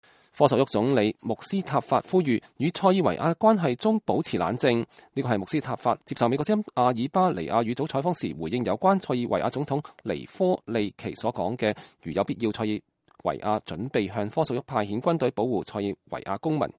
這是穆斯塔法接受美國之音阿爾巴尼亞語組採訪時回應有關塞爾維亞總統尼科利奇所說的“如有必要塞爾維亞準備向科索沃派遣軍隊保護塞爾維亞公民”。